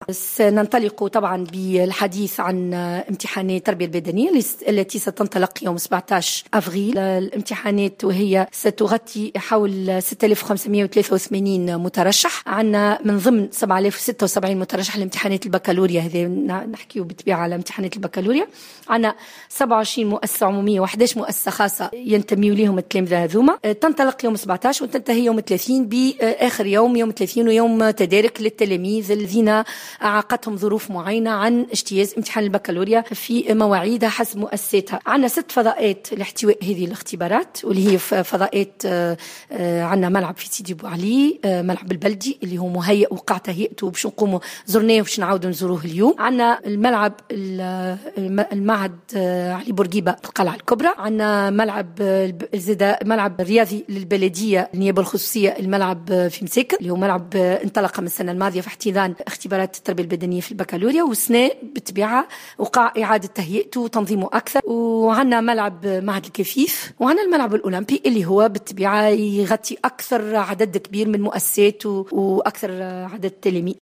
وأوضحت خضر في تصريح لمراسل الجوهرة اف ام، أن اختبارات التربية البدنيّة ستجرى في سوسة ب6 فضاءات، وهي كل من ملعب سيدي بوعلي والملعب البلدي وملعب معهد علي بورقيبة بالقلعة الكبرى والملعب الرياضي بمساكن والملعب الاولمبي وملعب معهد الكفيف.